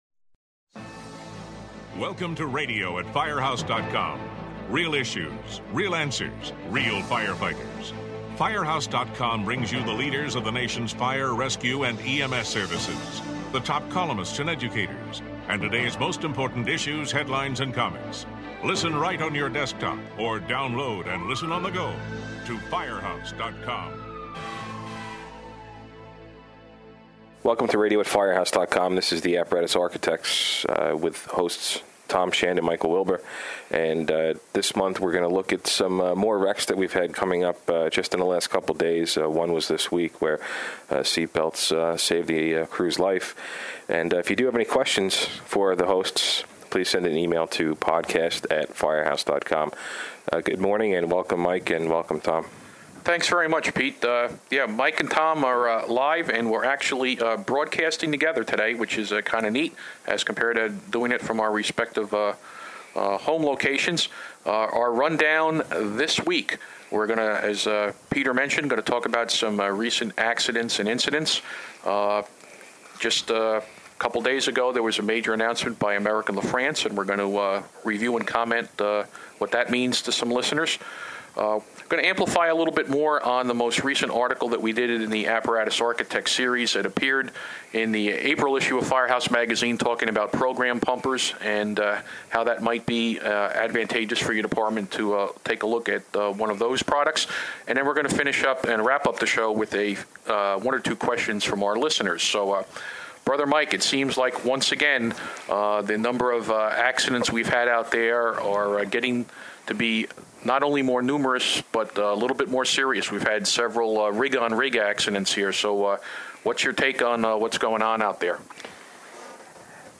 Listen as the two discuss some solid advice for departments who are working with American LaFrance to acquire new units.